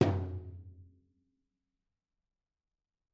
instruments / toms
toml-hitm-v4-rr2-mid.mp3